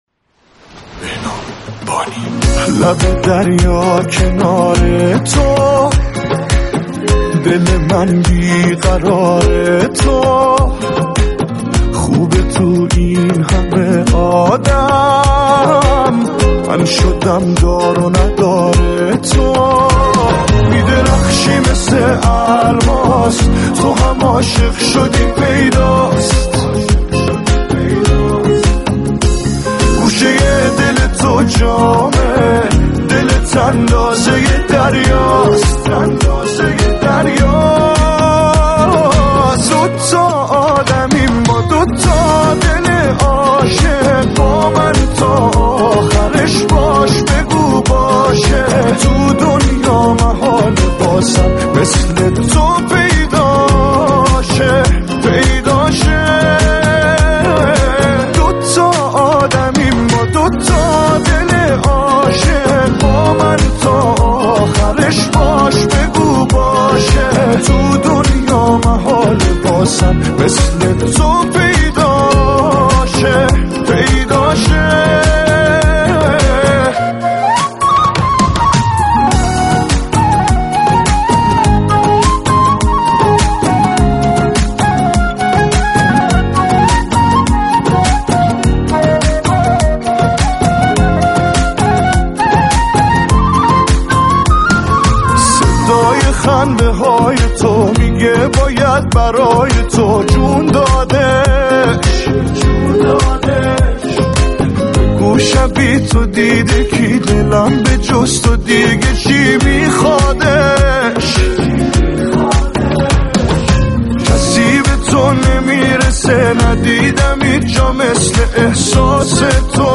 وی افزود: در آیتم نقدآهنگ برنامه «كافه هنر» مخاطبان برنامه نیز در كنار منتقدان و در تماس با برنامه، به نقد شفاهی آهنگ‌ها می‌پردازند.